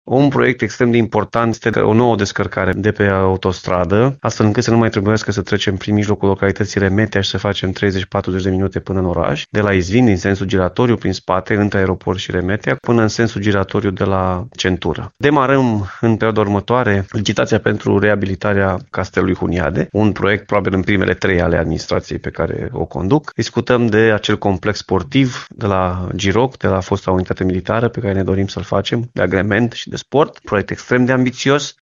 Președintele Consiliului Județean Timiș, Alfred Simonis, spune descărcarea de pe autostrada A va fi realizată ca o centură a localității Remetea Mare.